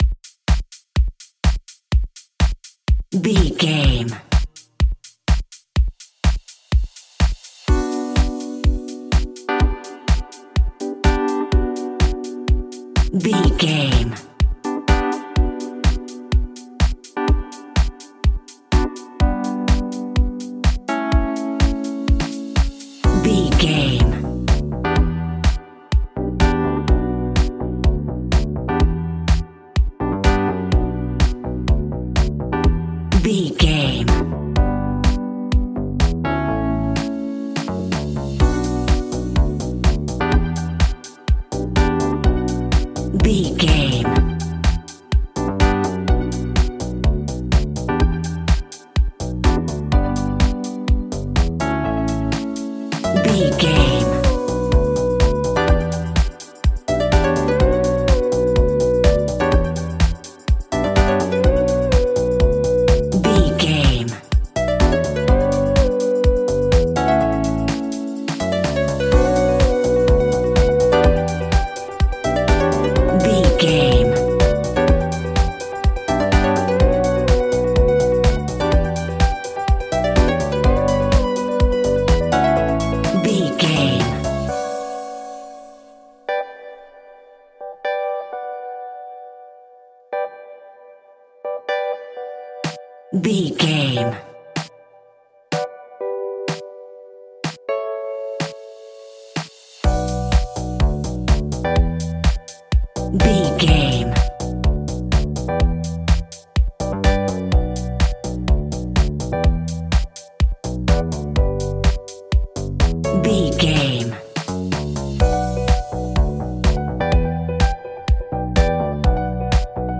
Aeolian/Minor
groovy
energetic
hypnotic
smooth
electric guitar
drum machine
synthesiser
electric piano
bass guitar
funky house
deep house
nu disco